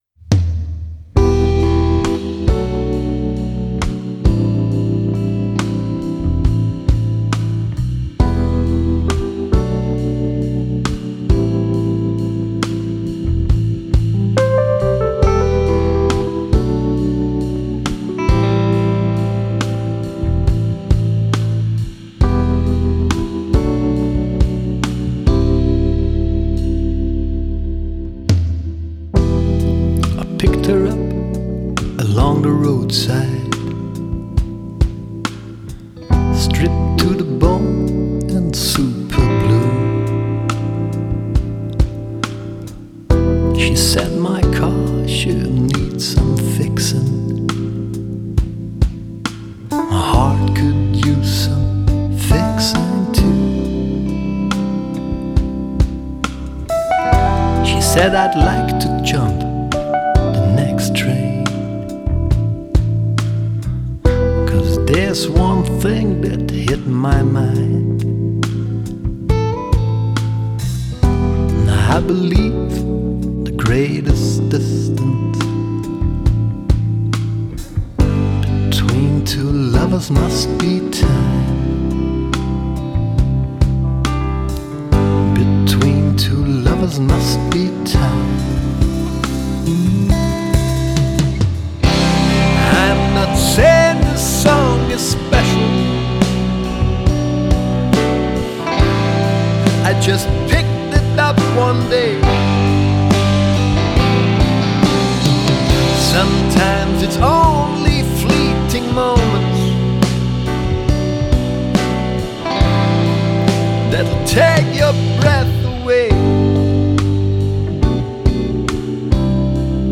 Не в тему предыдущей песни, но насчёт забойной, есть у меня одна... очень взрывная штучка... она у меня на будильнике стоит... никогда не проспите... и ещё и соседи спасибо скажут::))